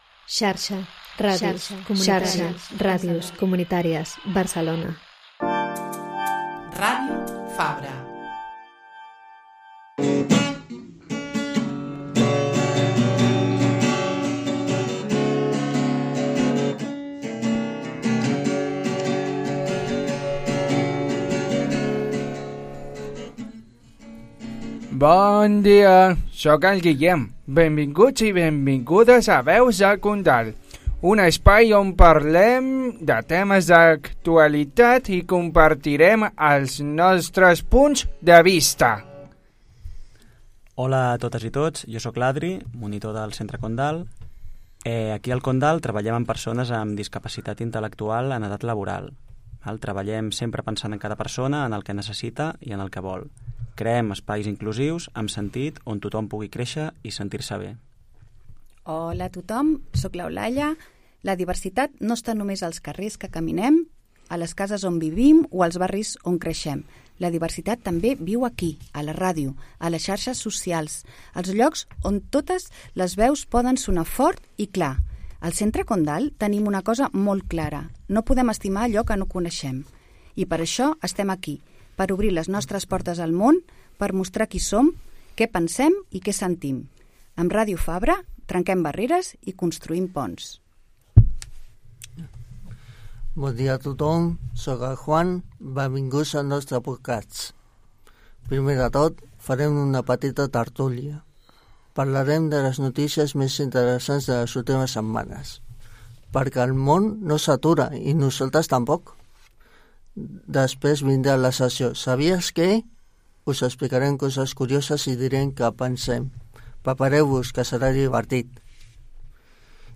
Sabies que la mel és l'únic aliment que no caduca mai? Més dades curioses, tertúlia i música en directe al nou programa de 'Les veus del Condal'.